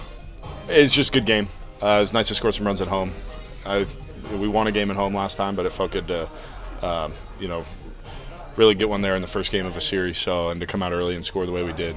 2. Royals 1B Vinnie Pasquantino on winning a series opener at home for the first time since May 9